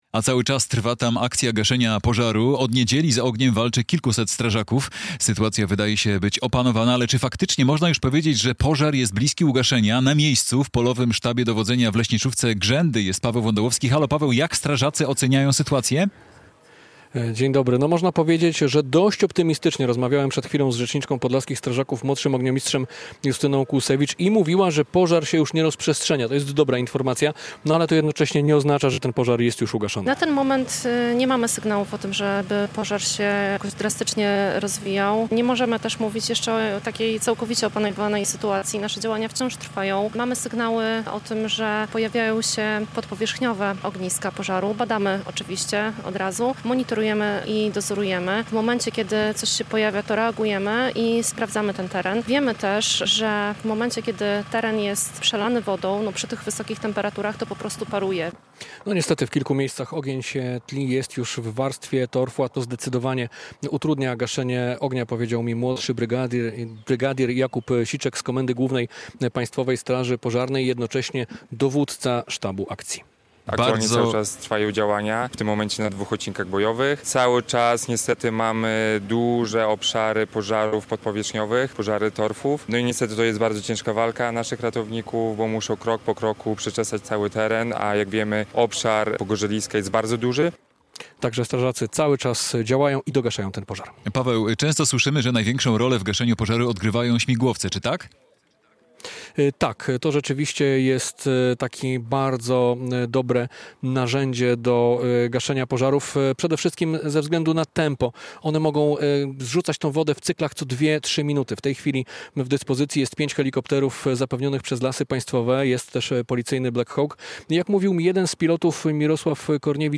Jak strażacy oceniają sytuację po pożarze w Biebrzańskim Parku Narodowym? - relacja